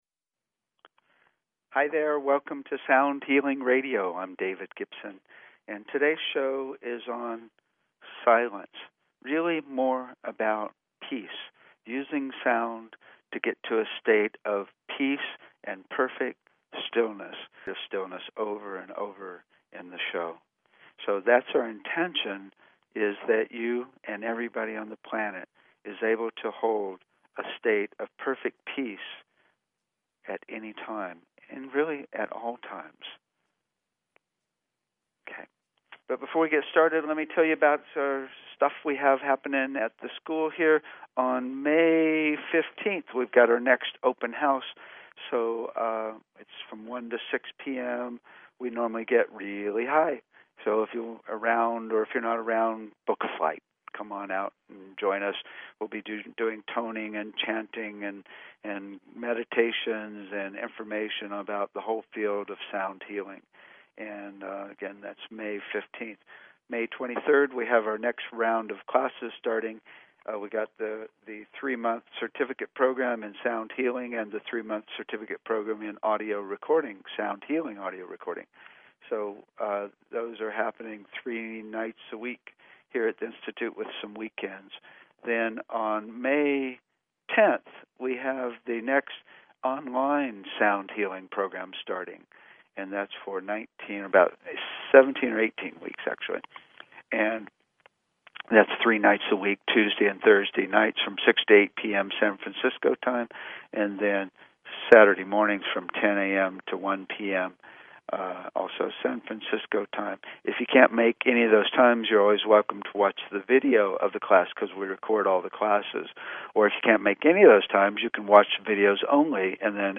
Talk Show Episode, Audio Podcast, Sound_Healing and Courtesy of BBS Radio on , show guests , about , categorized as
We will play many examples of slow fades and share the peace and stillness.